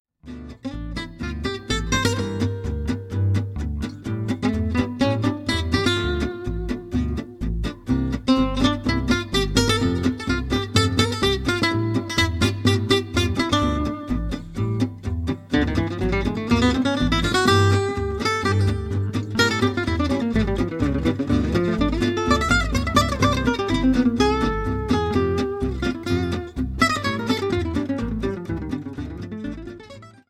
Plans d’impro dans le plus pur style Django.